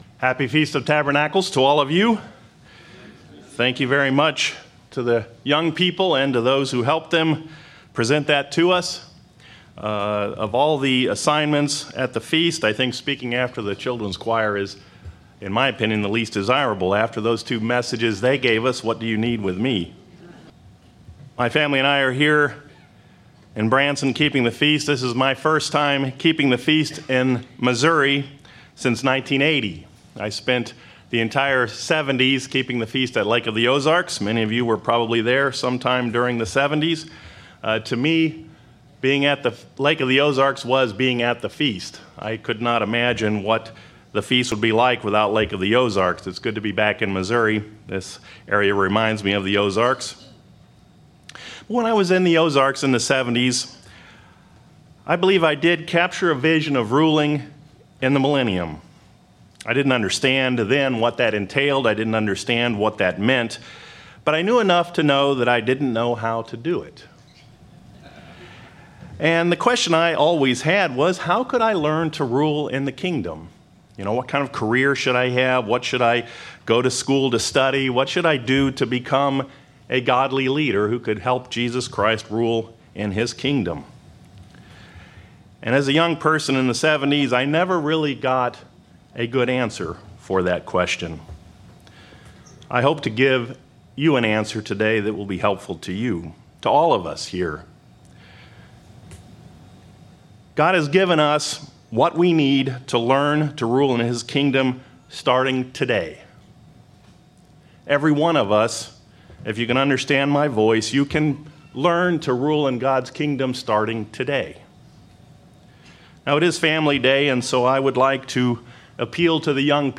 Given in Branson, Missouri